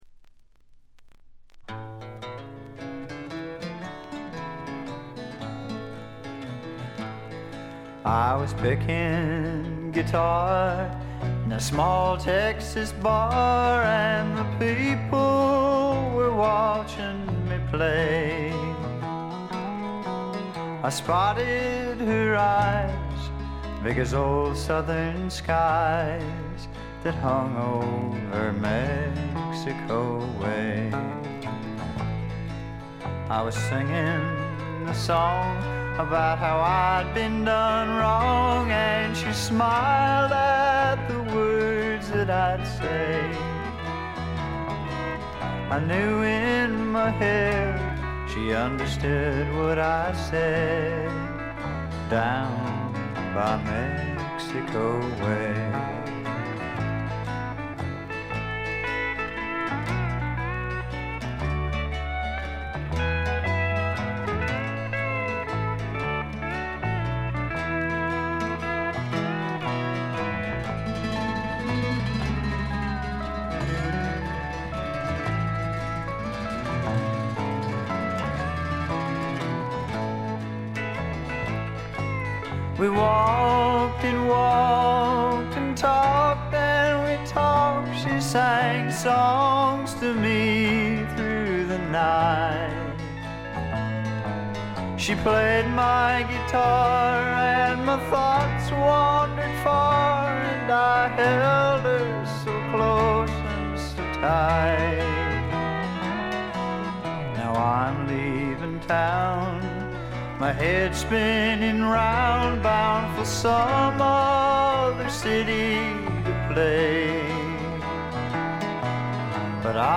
静音部で軽微なチリプチ少々。
試聴曲は現品からの取り込み音源です。